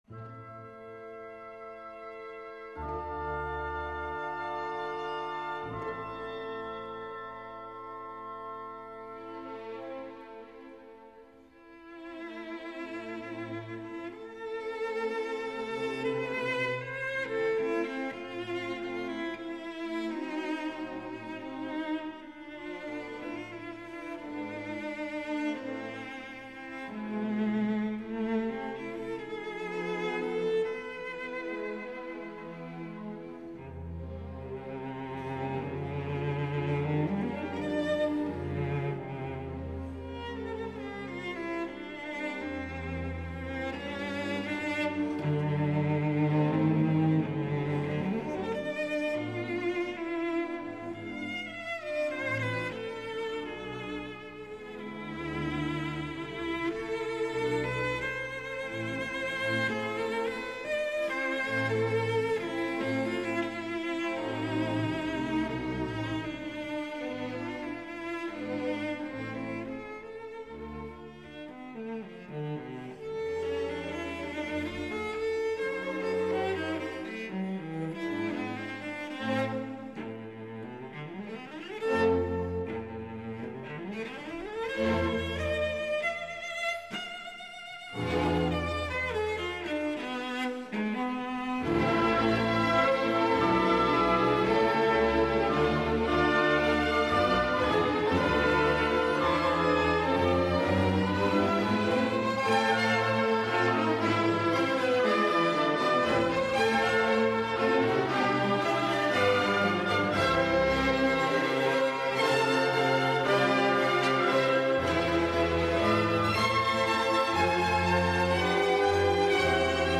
Mischa Maisky si è generosamente raccontato ai microfoni di ReteDue , poco prima di esibirsi per il Verbier Festival che lo ha avuto atteso ospite dopo un suo lungo periodo di inattività per ragioni di salute.